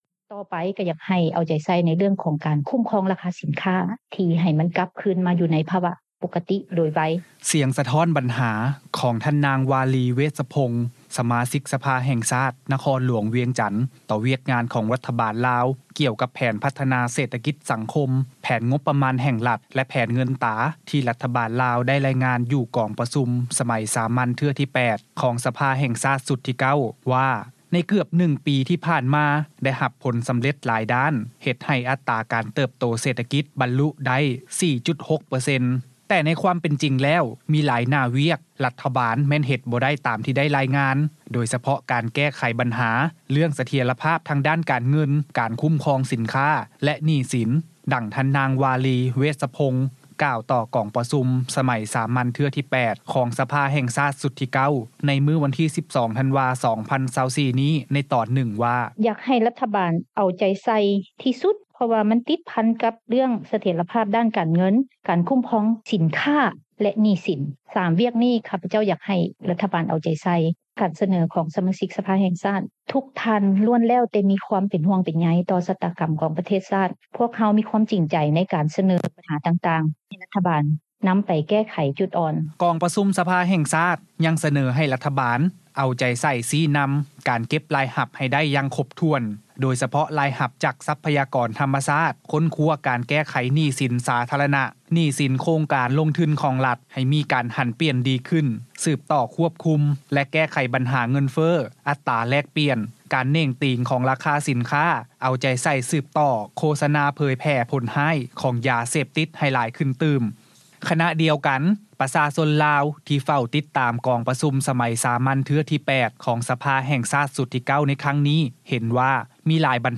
ສຽງສະທ້ອນບັນຫາ ຂອງທ່ານນາງ ວາລີ ເວດສະພົງ, ສະມາຊິກສະພາແຫ່ງຊາດ ນະຄອນຫຼວງວຽງຈັນ ຕໍ່ວຽກງານ ຂອງລັດຖະບານລາວ ກ່ຽວກັບແຜນ ພັດທະນາເສດຖະກິດ-ສັງຄົມ, ແຜນງົບປະມານ ແຫ່ງລັດ ແລະ ແຜນເງິນຕາ ທີ່ລັດຖະບານລາວ ໄດ້ລາຍງານ ຢູ່ກອງປະຊຸມ ສະໄໝສາມັນເທື່ອທີ 8 ຂອງສະພາແຫ່ງຊາດ ຊຸດທີ 9 ວ່າ ໃນເກືອບ 1 ປີ ທີ່ຜ່ານມາ ໄດ້ຮັບຜົນສໍາເລັດຫຼາຍດ້ານ ເຮັດໃຫ້ອັດຕາ ການເຕີບໂຕເສດຖະກິດ ບັນລຸໄດ້ 4.6%.